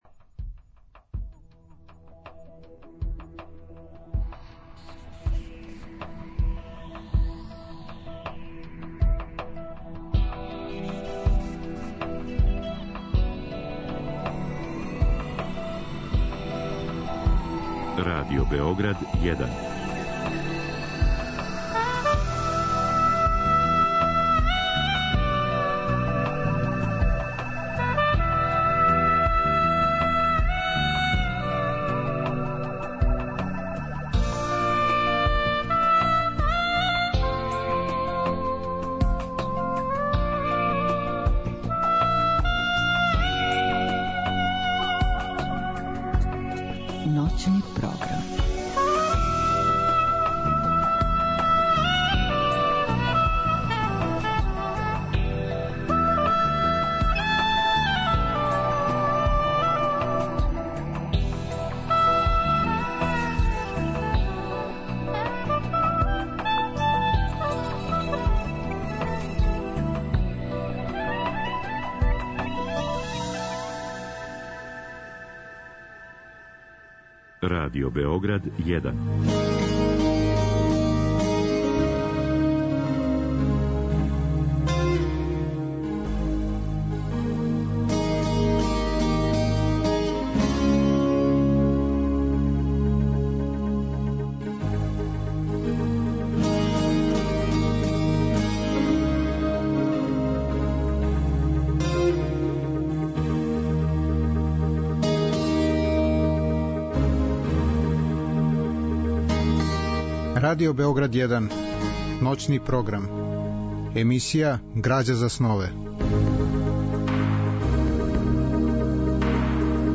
Разговор и добра музика требало би да кроз ову емисију и сами постану грађа за снове.